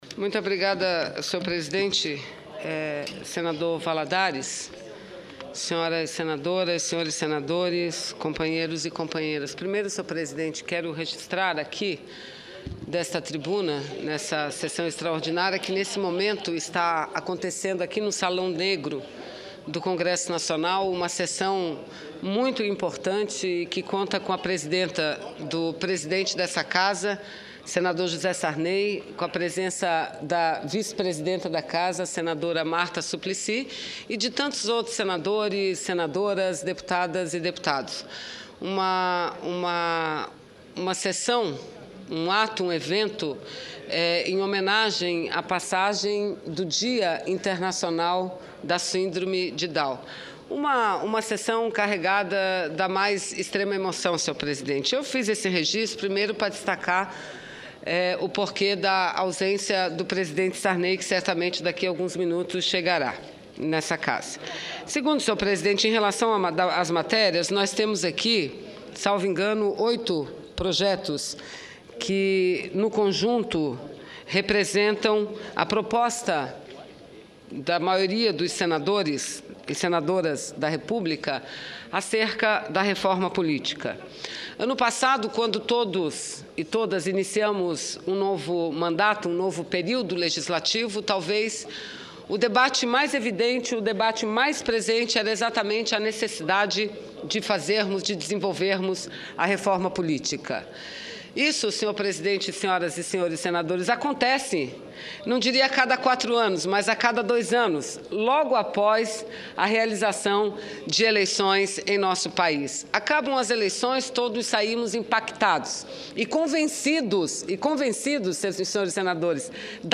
Pronunciamento da senadora Vanessa Grazziotin